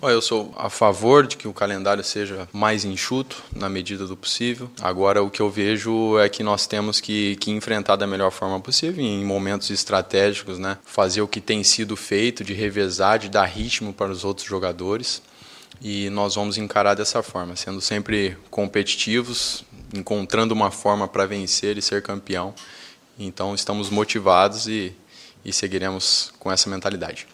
Em coletiva, ele admitiu que é a favor de um enxugamento do Estadual, mas não desvalorizou a competição.